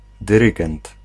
Ääntäminen
IPA : /kənˈdʌktɚ/